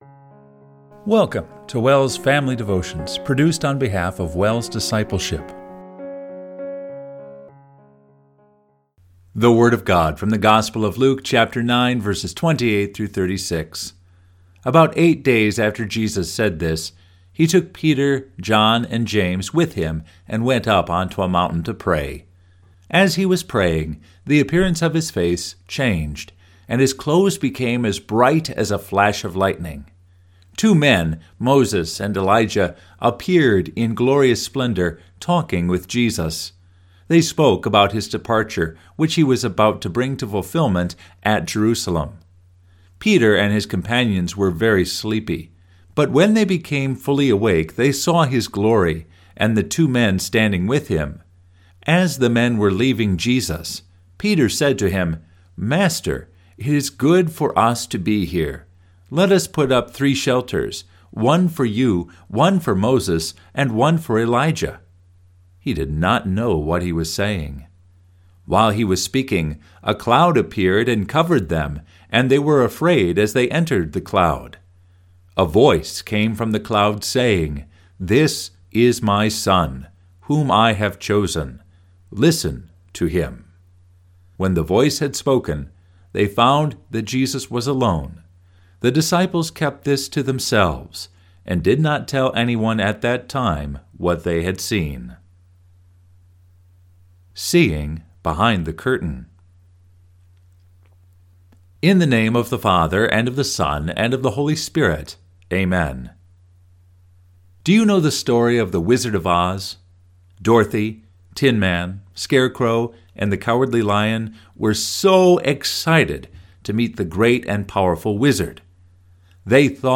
Family Devotion – March 3, 2025